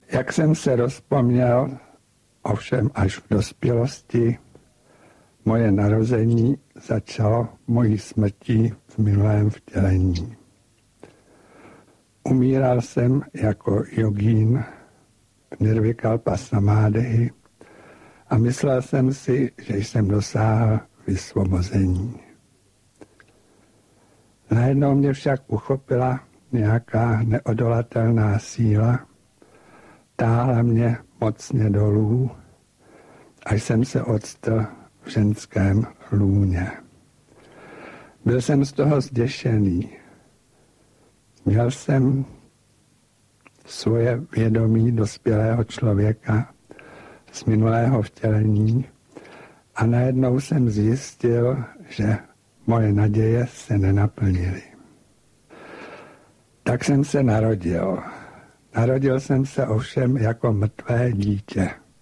Záznam přednášky v Žilině z roku 1995. Nejedná se o studiovou nahrávku.